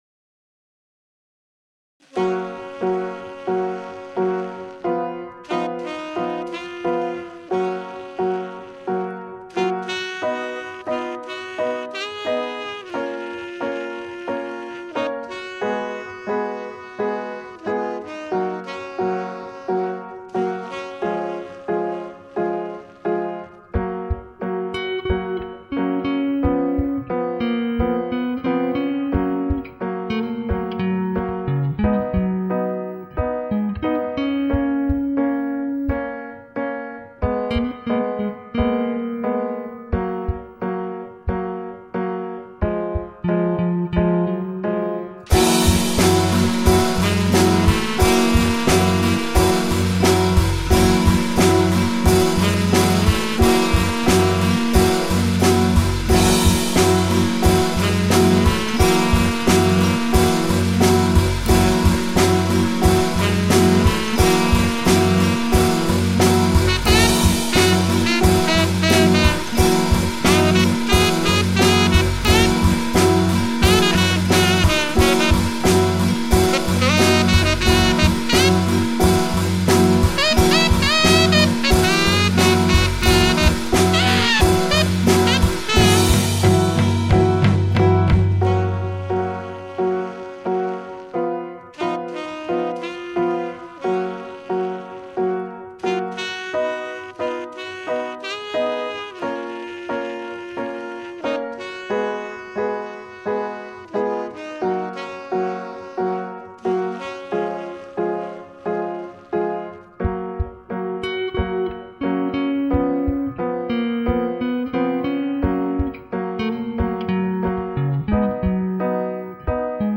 Jazz with a story...
Tagged as: Jazz, Alt Rock